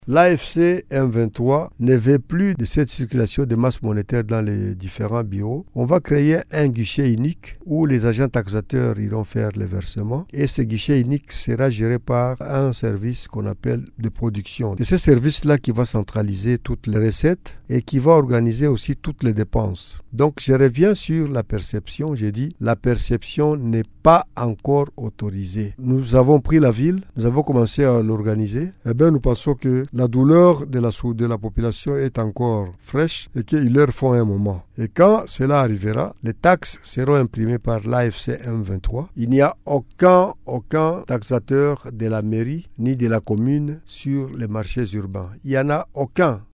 Cet appel est du maire de Bukavu Ladys MUGANZA WAKANDWA dans un entretien avec Radio Maendeleo.